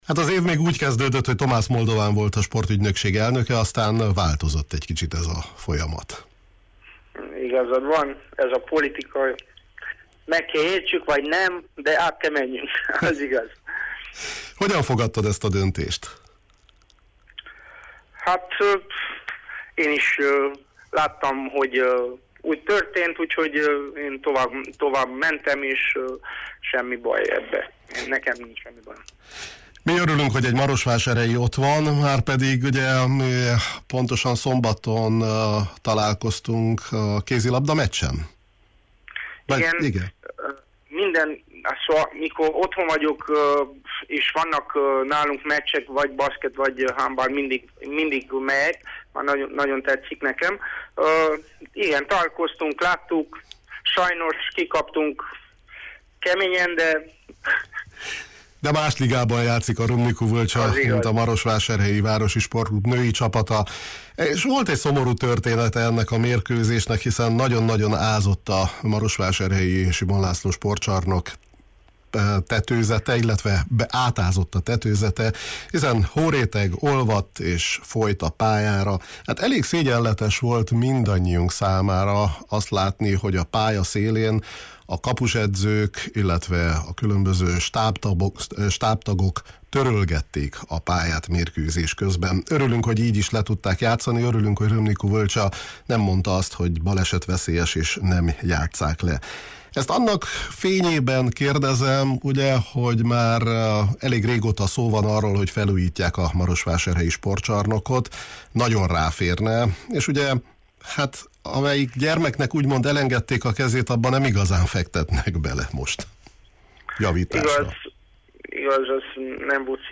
Thomas Moldovan, a Román Sportügynökség volt vezetője, jelenlegi alelnöke beszélt a témáról a Kispad sportműsorunkban